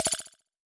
Percussion Echo Notification2.wav